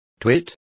Complete with pronunciation of the translation of twits.